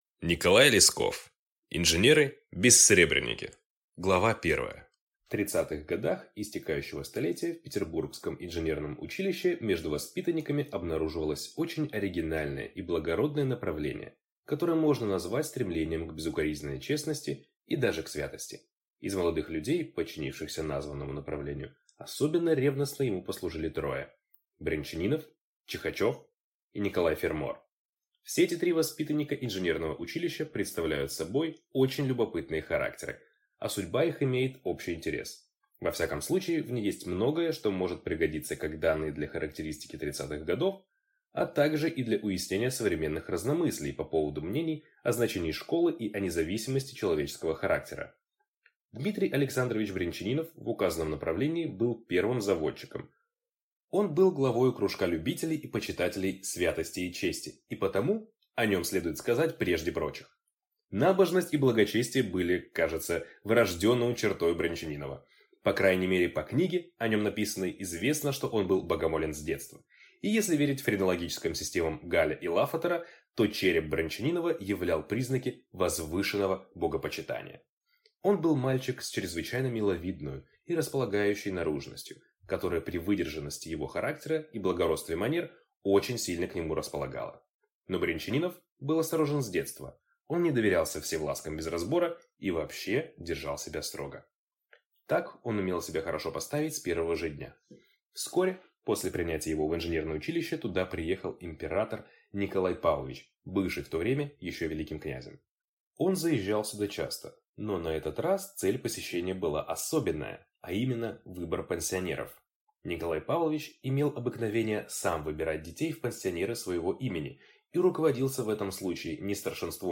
Аудиокнига Инженеры-бессребреники | Библиотека аудиокниг